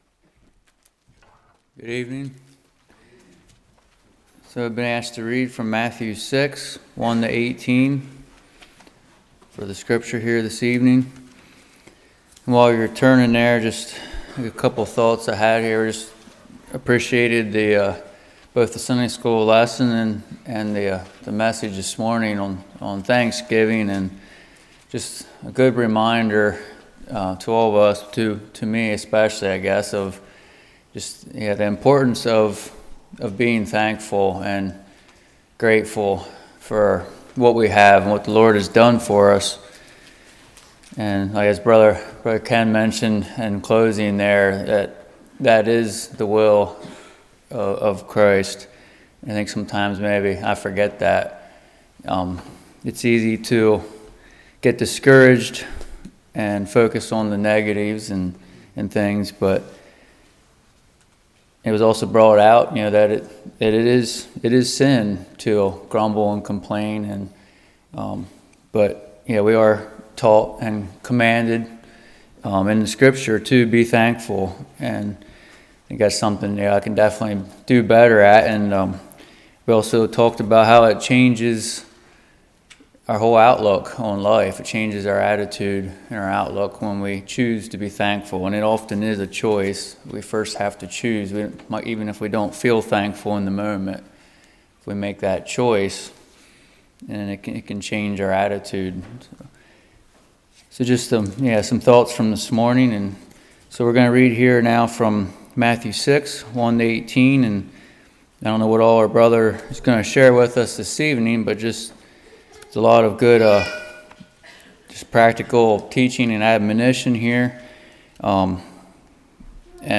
Matthew 6:1-18 Service Type: Evening Giving